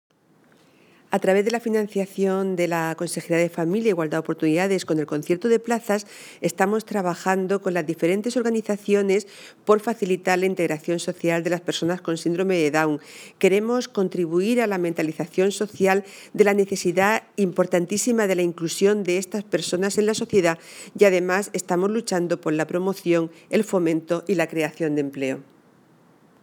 Ver imagen a tamaño real Declaraciones de la consejera de Familia e Igualdad de Oportunidades, Violante Tomás, con motivo del Día Mundial de las personas con Síndrome de Down (Documento [.mp3] 0,65 MB) Destacados Conciliación laboral (SMAC) e-Tributos Pago a Acreedores Participación ciudadana Canal Mar Menor © Todos los derechos reservados.